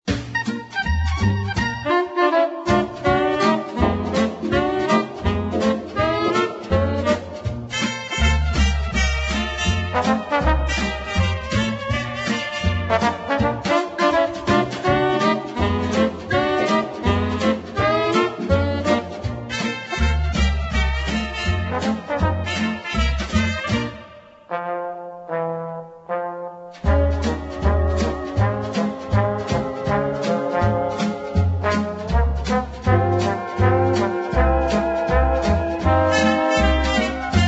1962 funny medium instr.